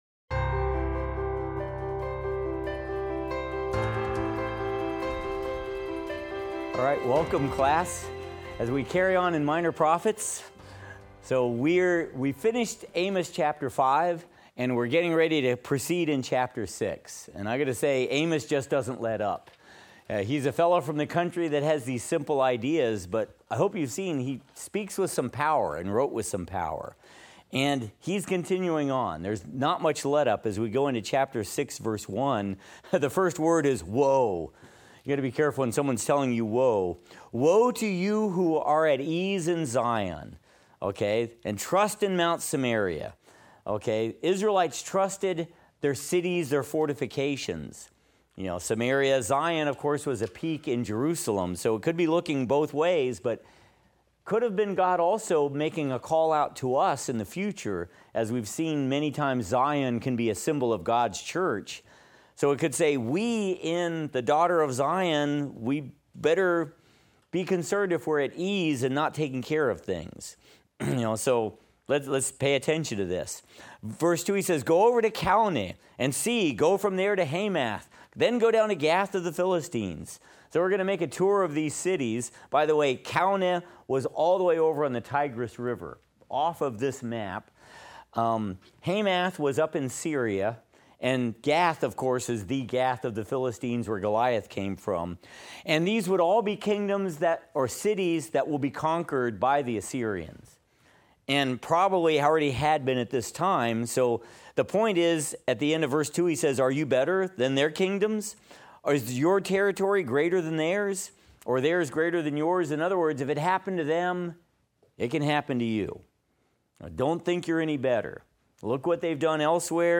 Minor Prophets - Lecture 11 - audio.mp3